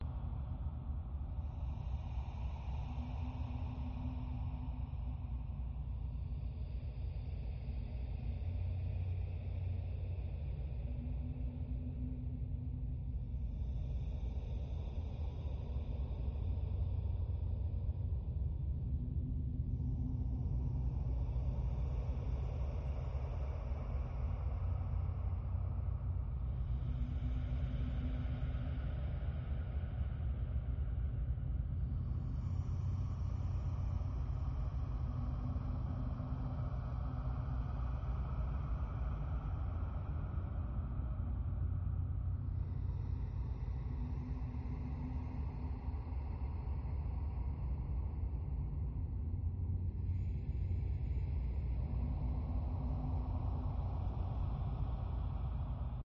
Здесь вы найдете саундтреки, фоновые шумы, скрипы, шаги и другие жуткие аудиоэффекты, создающие неповторимую атмосферу ужаса.
Mirror Chamber Breathing